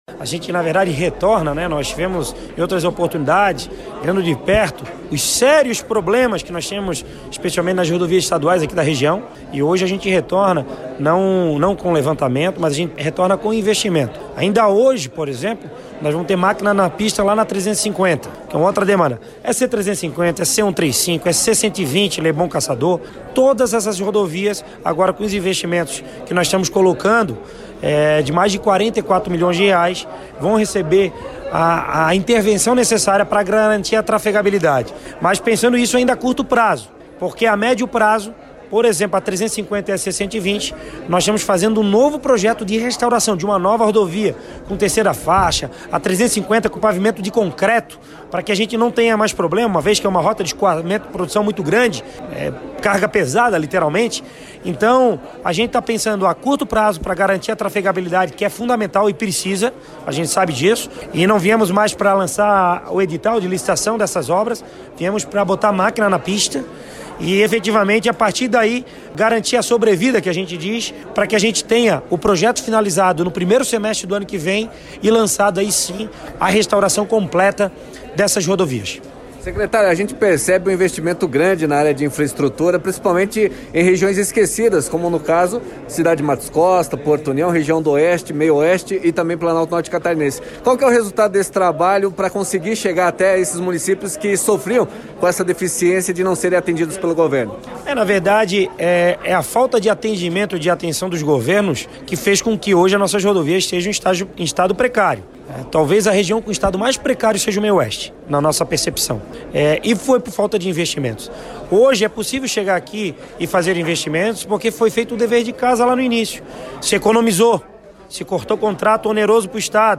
SECRETARIO-DE-INFRAESTRUTURA-TIAGO-VIEIRA.mp3